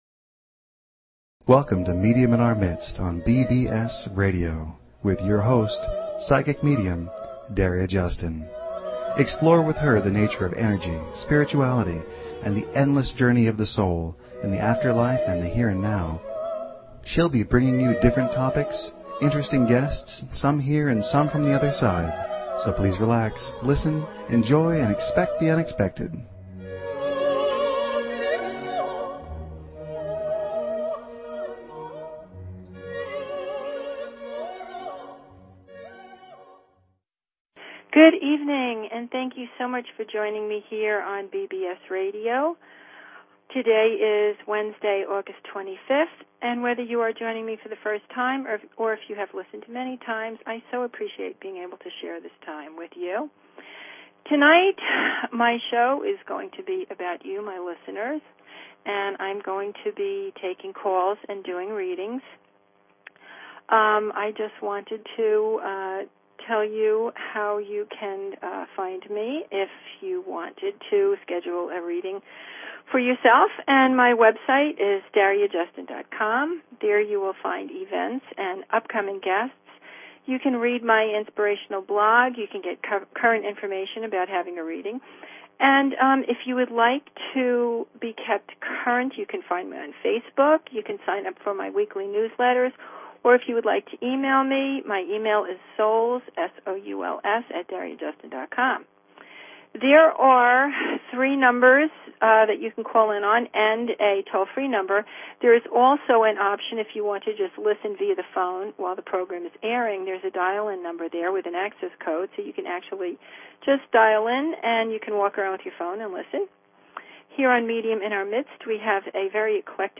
Talk Show Episode, Audio Podcast, Medium_in_our_Midst and Courtesy of BBS Radio on , show guests , about , categorized as